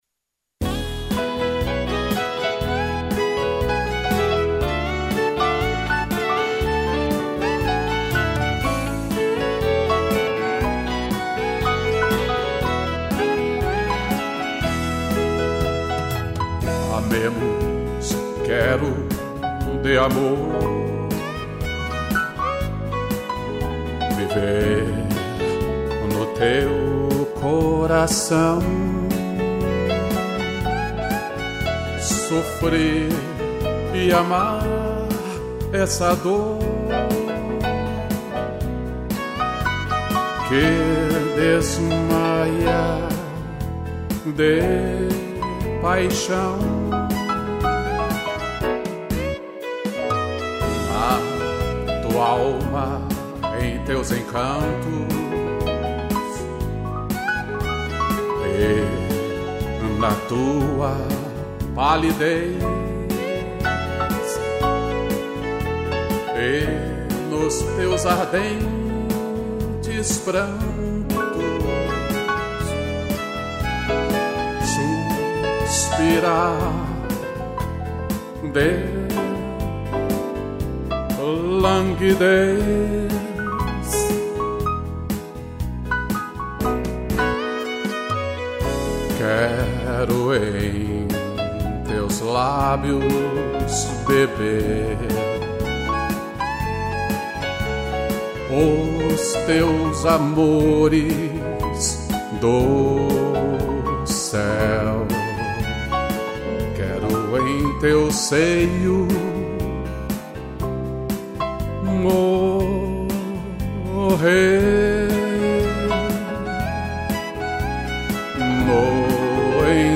piano e violino